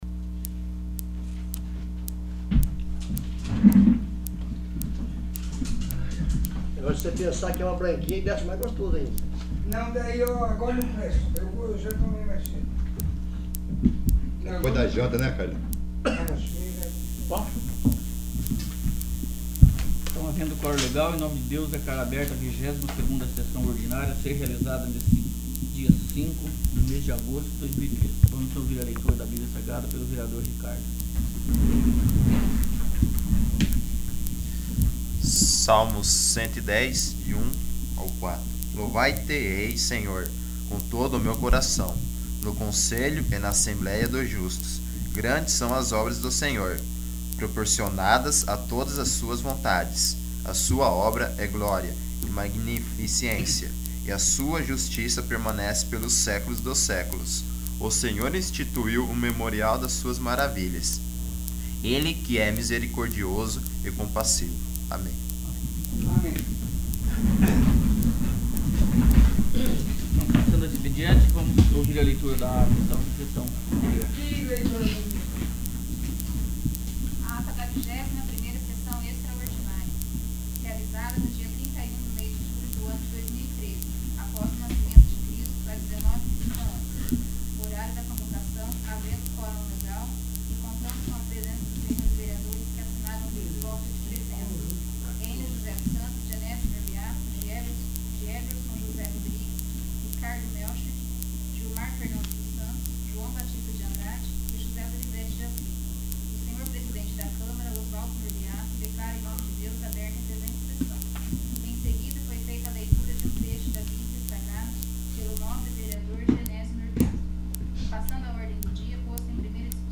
22º. Sessão Ordinária